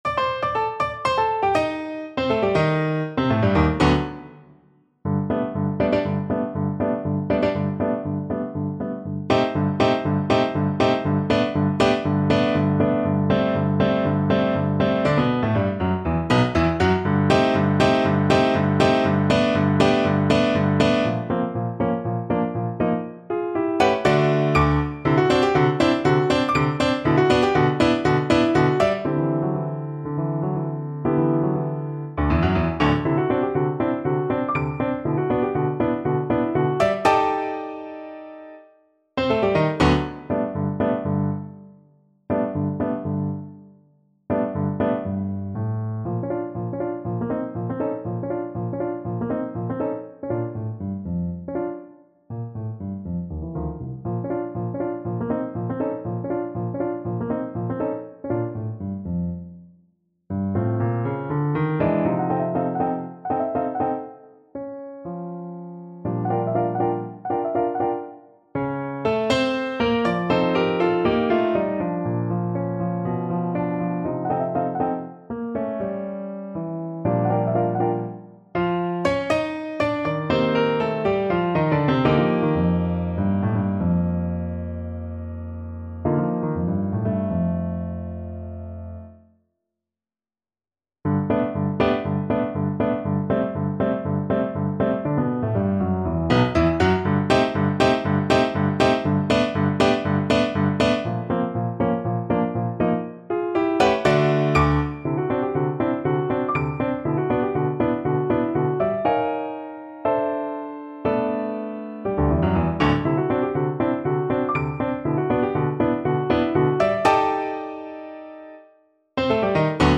2/4 (View more 2/4 Music)
Allegro giusto (View more music marked Allegro)
Classical (View more Classical Viola Music)